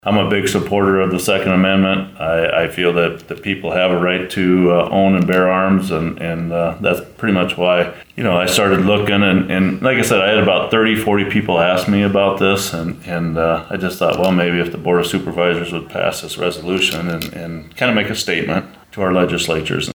kruger-on-2nd-amendment-2.mp3